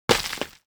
UI_StoneFract_01.ogg